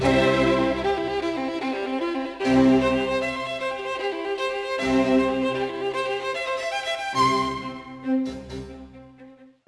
Third Movement: Allegro - F Major
The third solo depicts 'The Wild Beast Fleeing', the soloist playing semiquaver triplets: